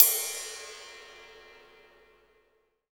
D2 RIDE-09-R.wav